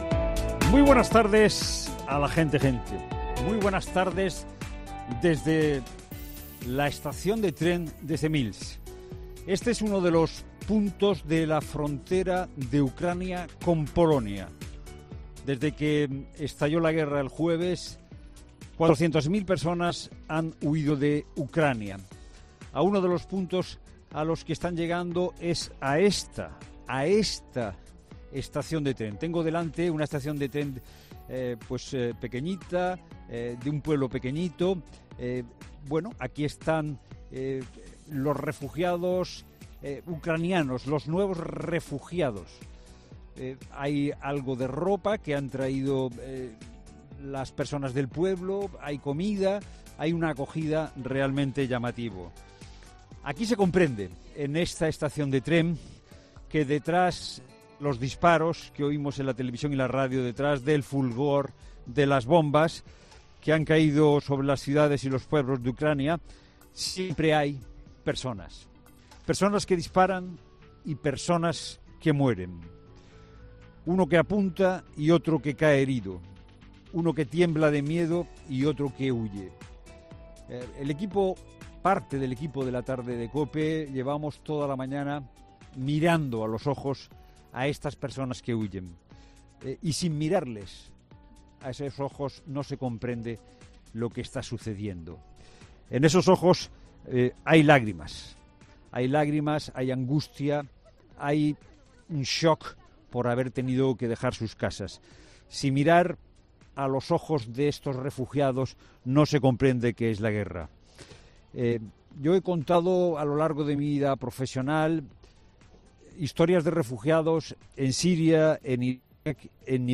en la frontera: Sin ver las lágrimas de estas personas no se comprende la guerra